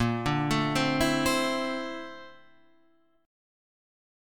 A#M#11 chord